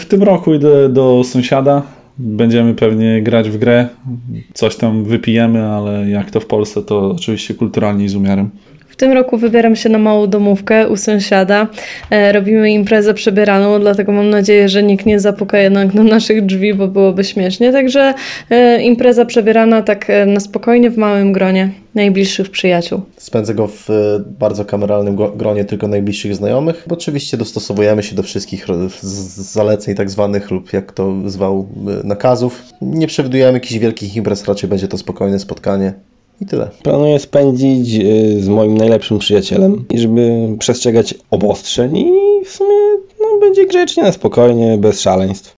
Na domówkach w gronie kilku najbliższych osób – tak sylwestra planują spędzić mieszkańcy regionu, z którymi rozmawiał reporter Radia 5.